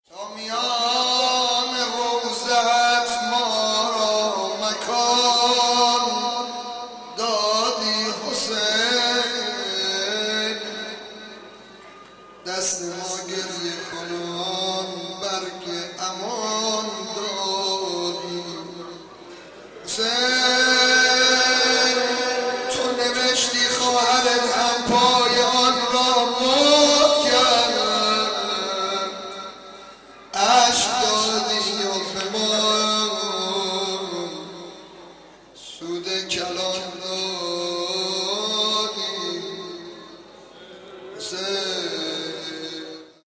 مجمع انصارالحسین (ع) نیشابور